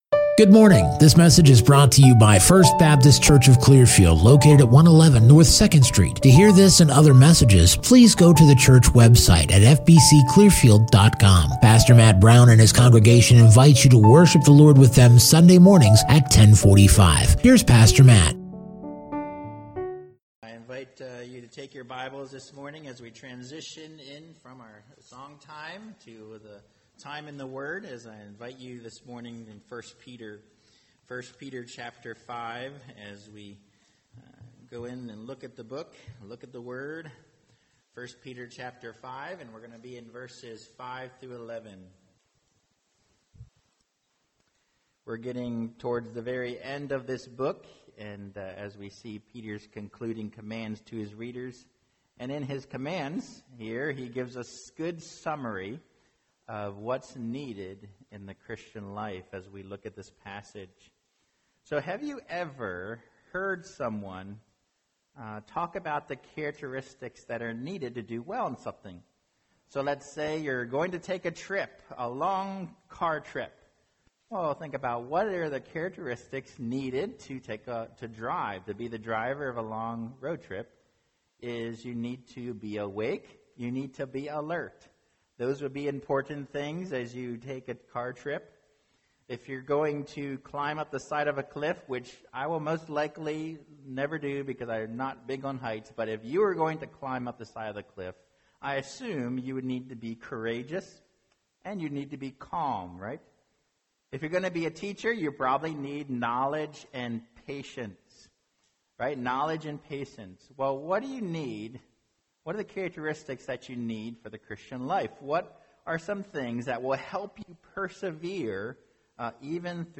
2021 Humble & Watchful Preacher